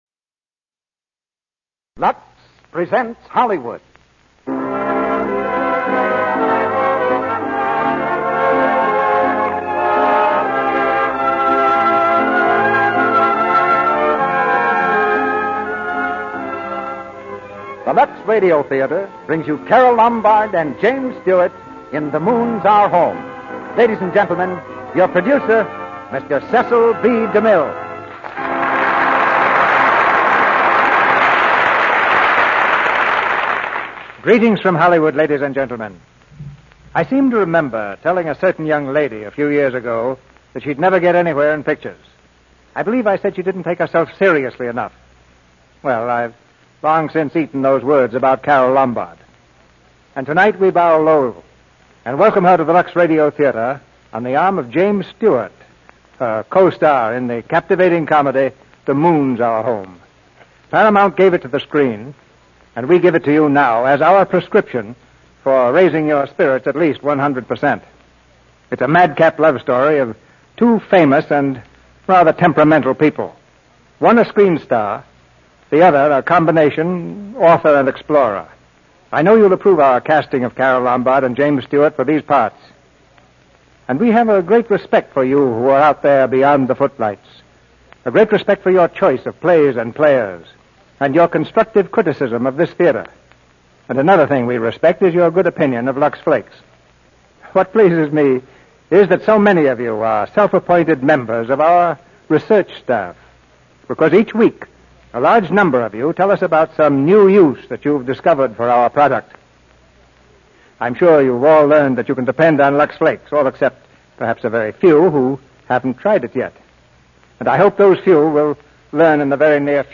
The Moon's Our Home, starring James Stewart, Carole Lombard, Clara Blandick